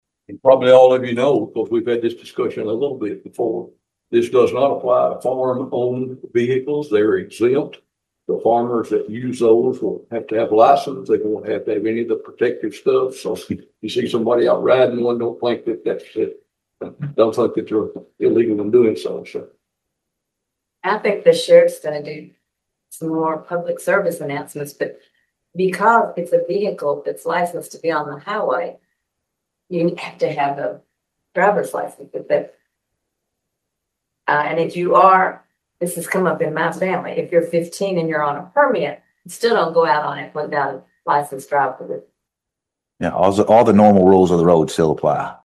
Hopkins County Fiscal Court is one step closer to allowing street-legal special purpose vehicles on certain local and state roads, following the approval of an ordinance on first reading at Tuesday morning’s meeting.